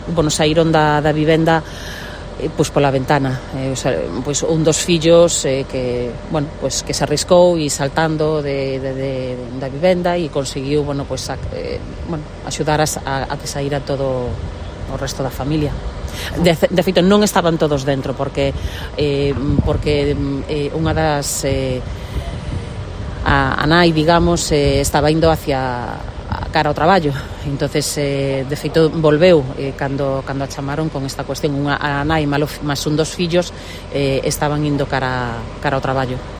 Alejandra Pérez, alcaldesa de Bergondo